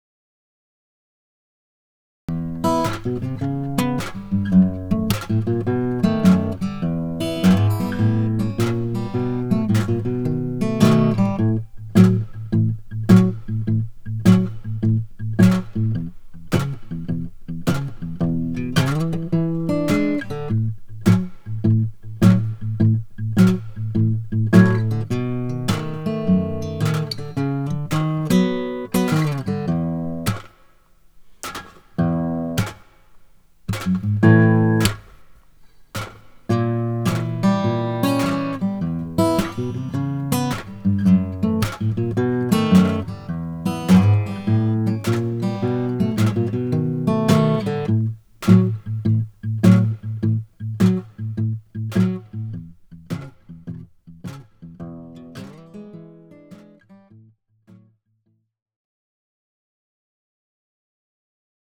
Tab e Base Musicale della Lezione